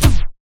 HISS K.SHORT.wav